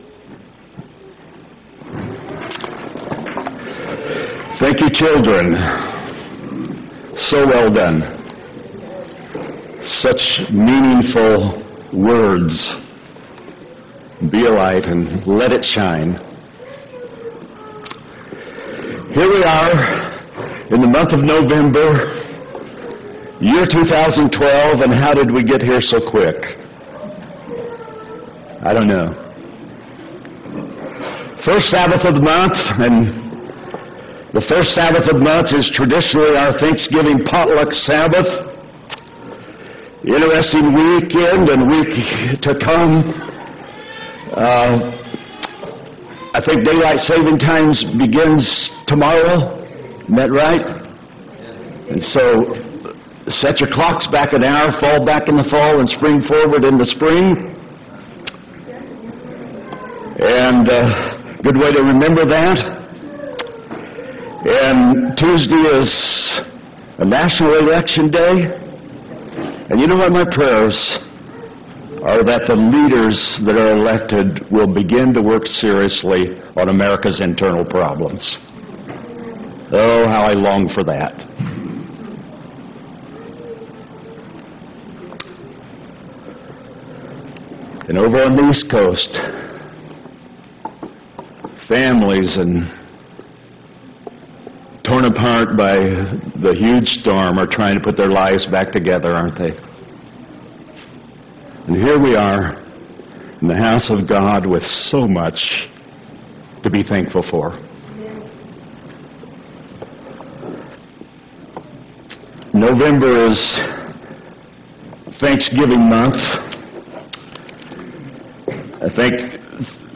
11-3-12 sermon
11-3-12-sermon.m4a